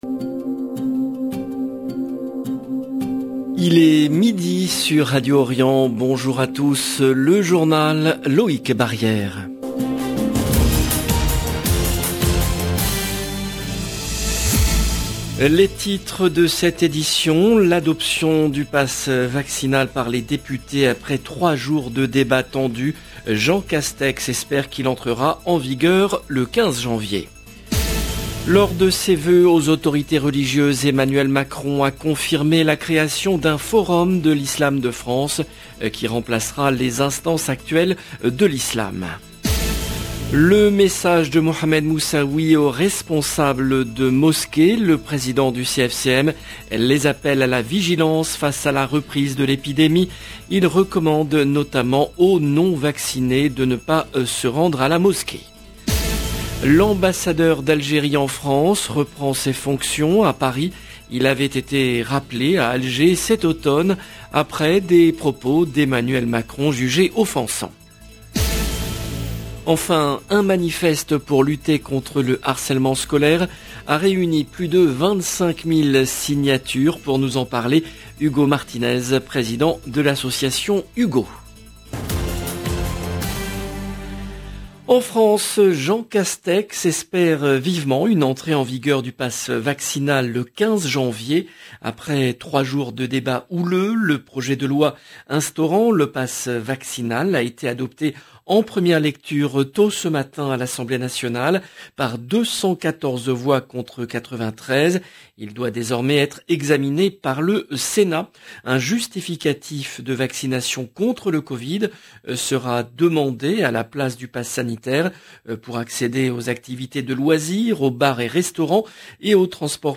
LE JOURNAL EN LANGUE FRANCAISE DE MIDI DU 6/01/22